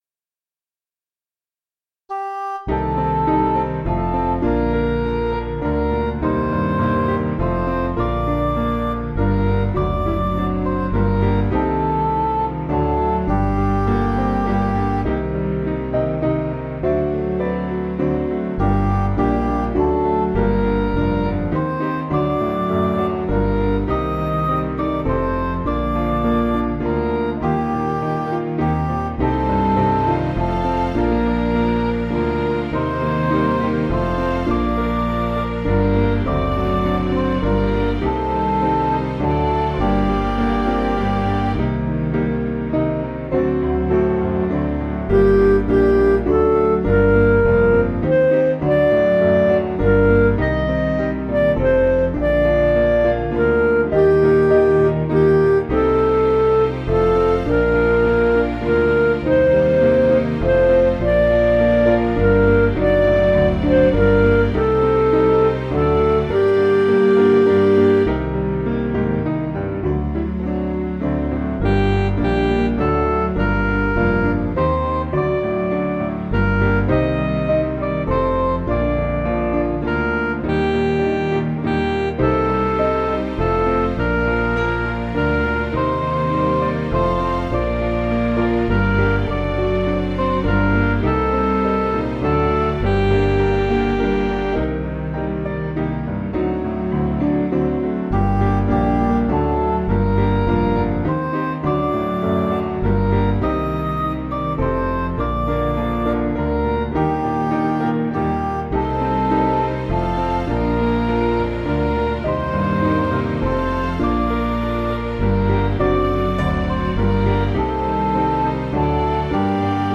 Piano & Instrumental
(CM)   6/Gm